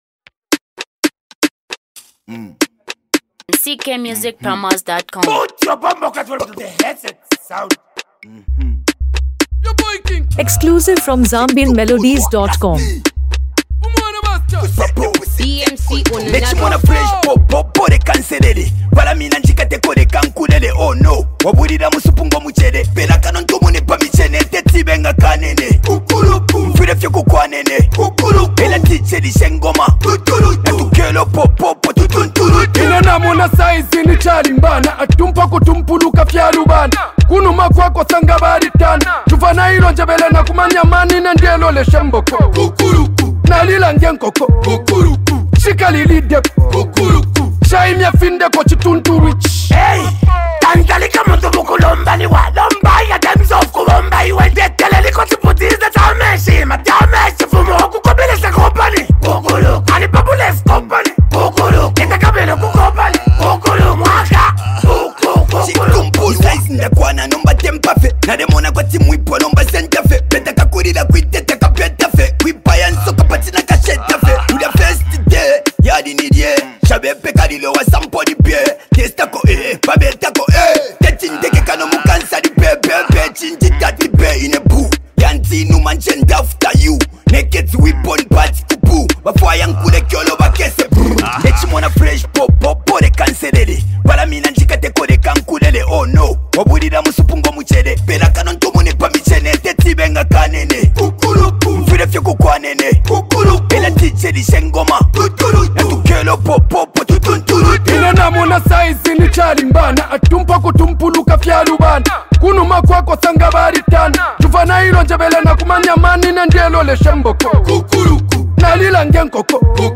Zambian Music
A Must-Listen Afrobeat Vibe